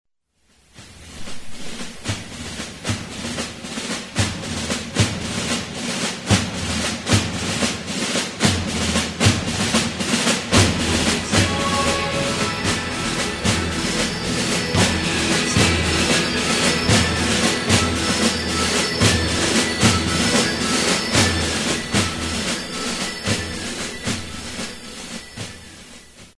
African drummers segment